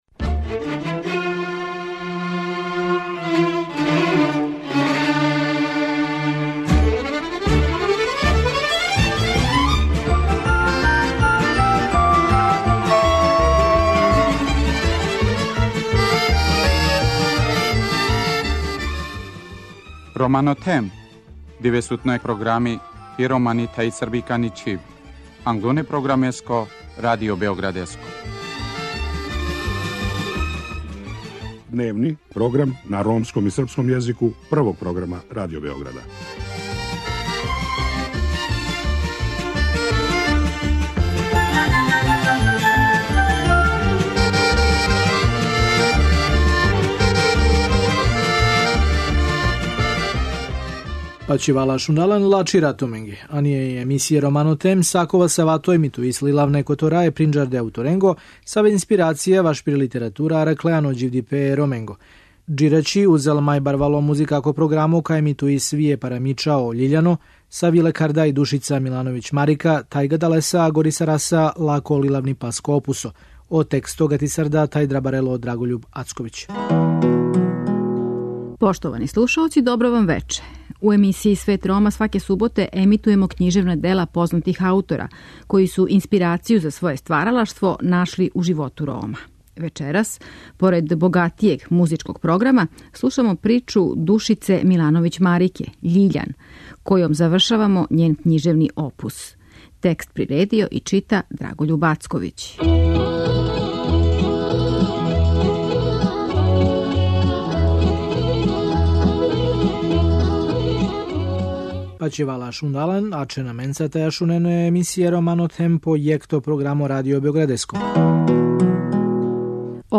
Вечерас, поред богатијег музичког програма, у оквиру ромске књижевне антологије слушамо причу Душице Милановић Марике 'Љиљан', којом завршавамо њен књижевни опус.